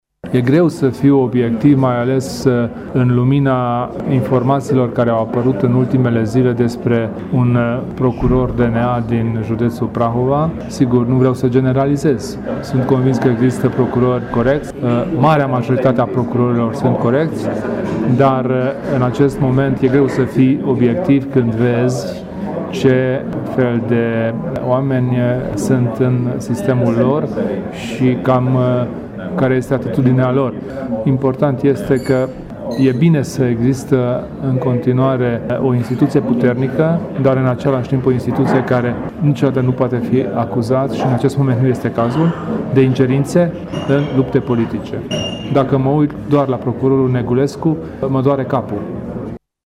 Declaraţia a fost făcută azi, la Sfântu Gheorghe, Kelemen Hunor răspunzând unei intrebări legate de activitatea DNA, în contextul prezentării bilanţului acestei instituţii pe anul 2016.